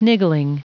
Prononciation du mot niggling en anglais (fichier audio)
Prononciation du mot : niggling